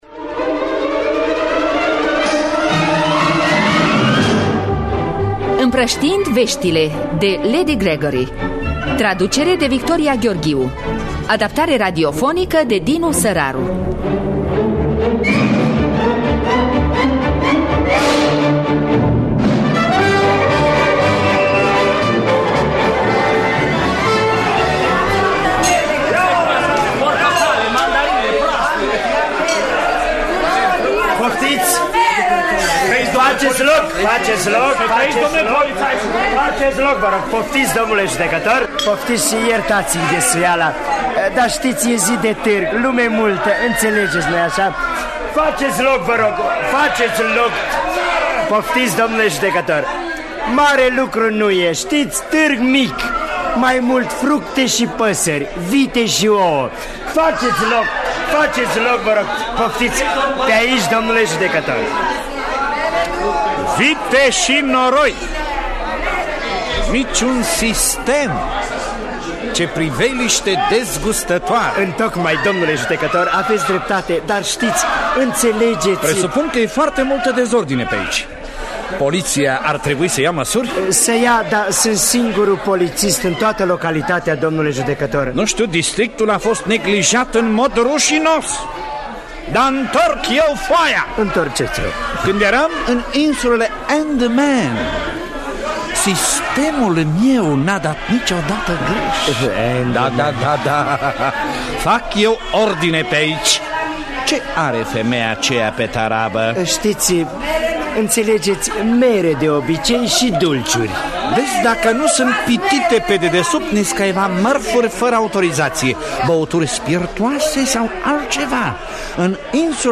Împrăștiind veștile de Lady Gregory – Teatru Radiofonic Online
Adaptarea radiofonică de Dinu Săraru.